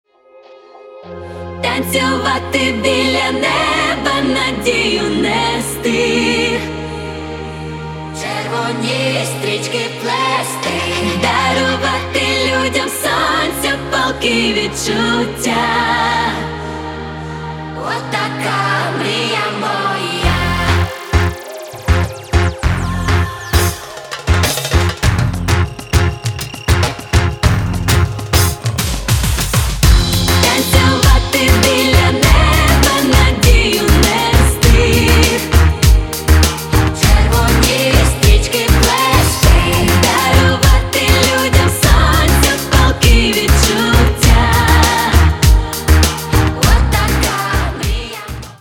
• Качество: 320, Stereo
поп
Dance Pop
Electropop